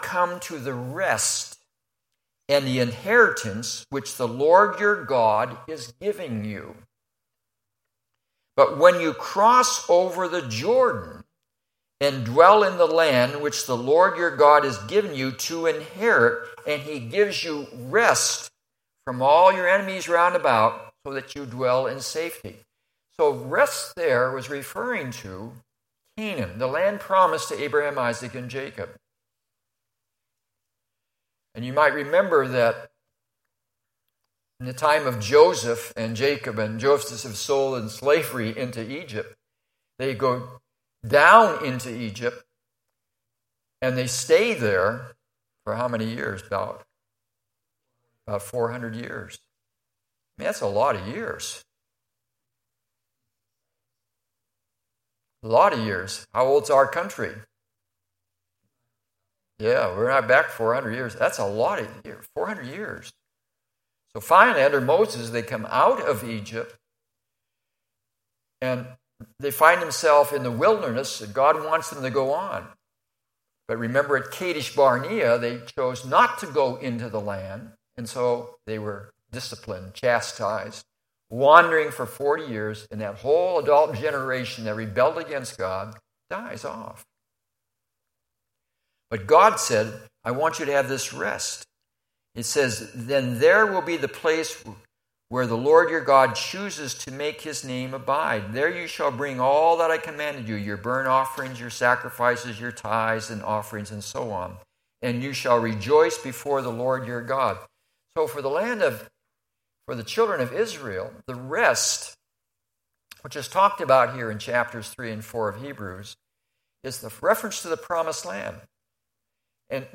Sunday Evening Message